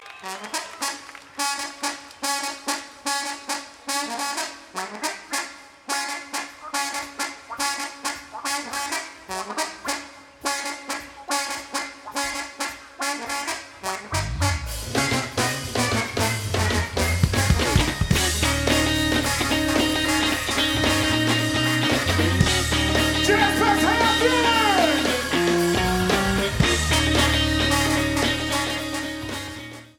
Jamband
R&B
Rock